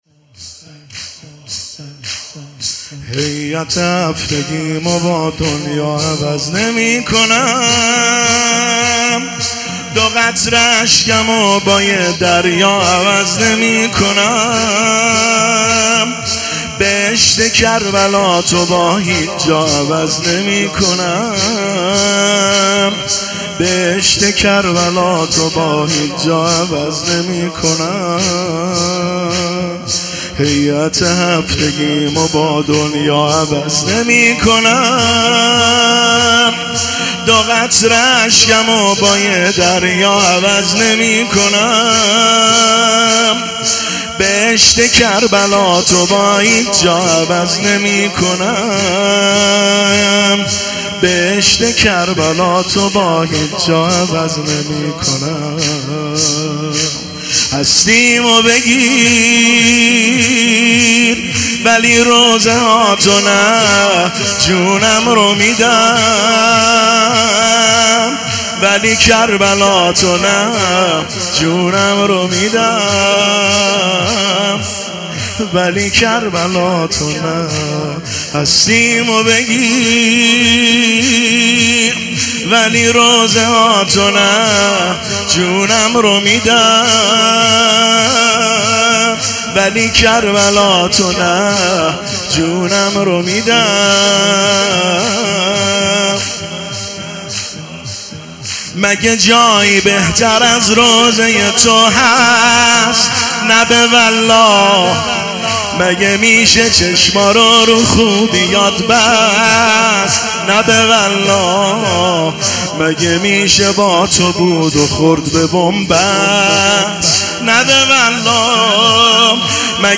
مداحی زمینه